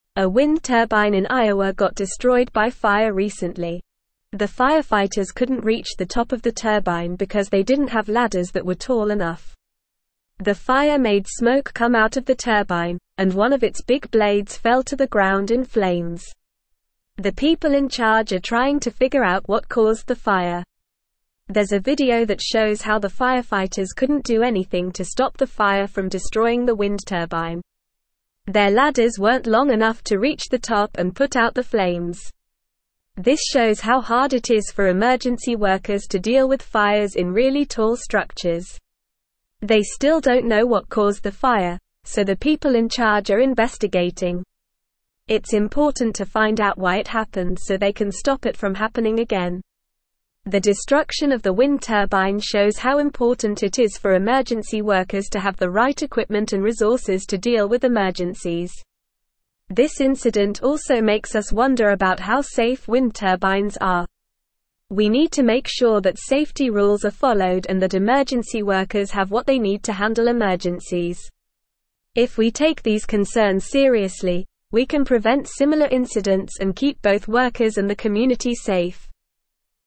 Normal
English-Newsroom-Upper-Intermediate-NORMAL-Reading-Fire-Destroys-Wind-Turbine-Due-to-Lack-of-Equipment.mp3